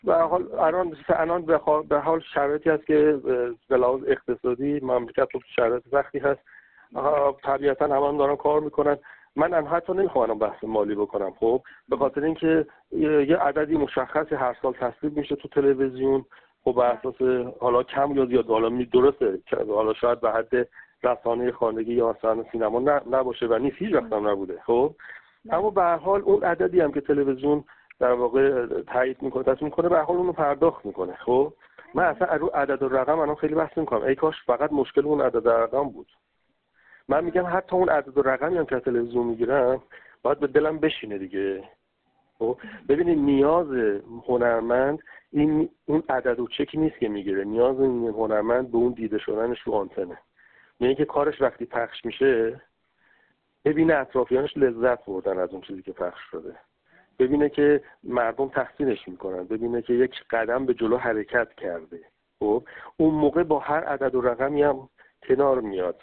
او در گفت‌وگو با خبرنگار جام‌جم تاکید می‌کند نیاز هنرمند دیده شدن است، نه این عدد و رقم‌ها.